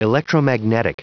Prononciation du mot electromagnetic en anglais (fichier audio)